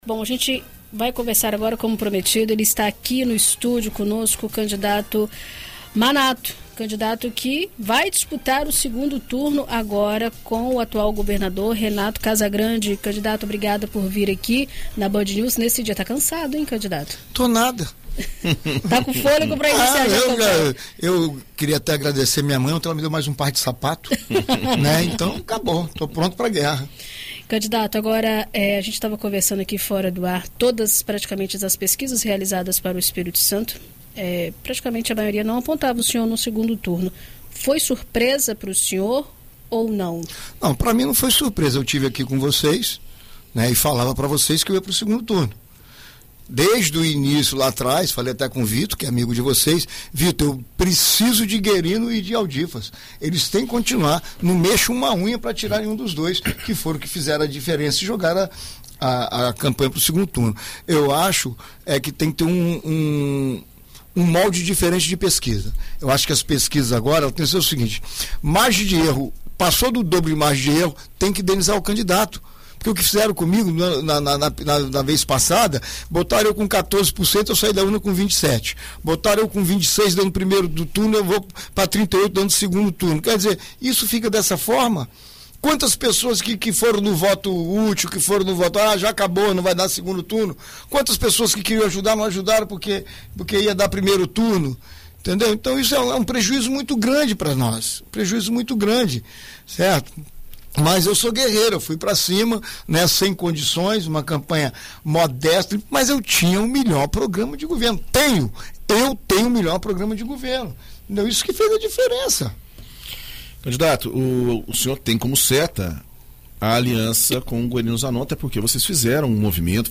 Em entrevista à BandNews FM Espírito Santo, o candidato do PL rebateu críticas do atual governador de uma possível retomada do crime organizado, caso Manato seja eleito